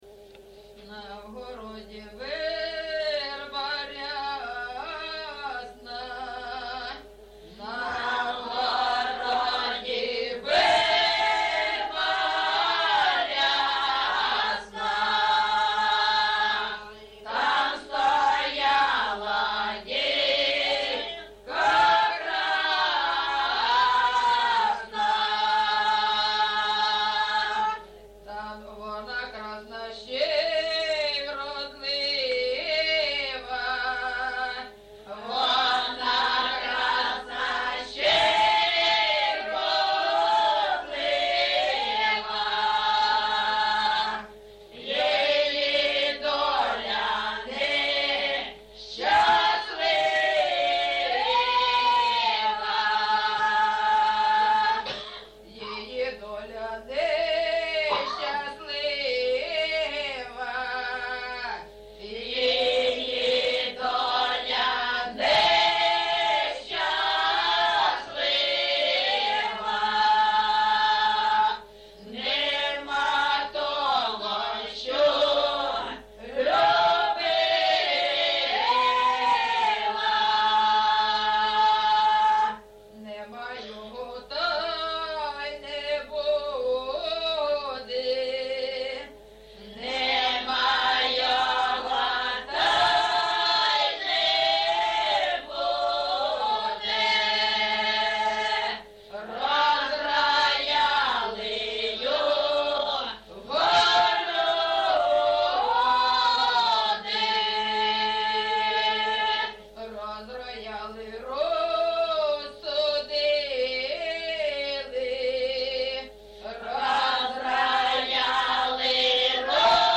ЖанрПісні з особистого та родинного життя
Місце записус-ще Щербинівка, Бахмутський район, Донецька обл., Україна, Слобожанщина